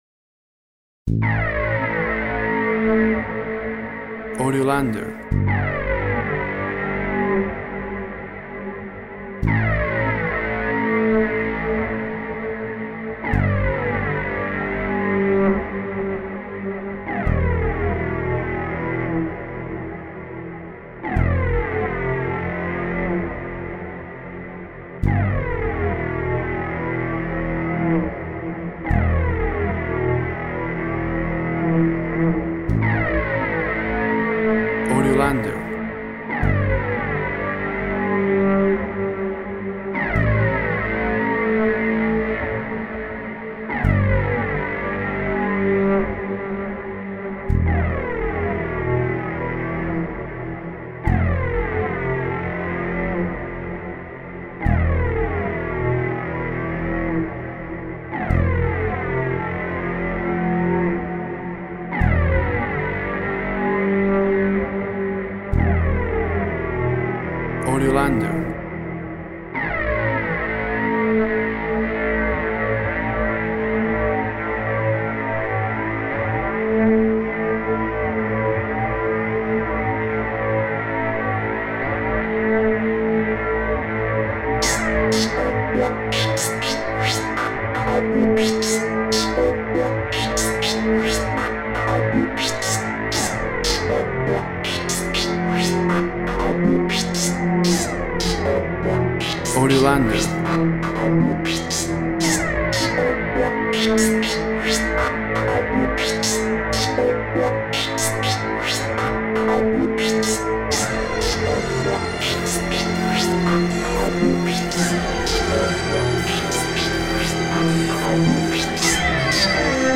Tempo (BPM) 129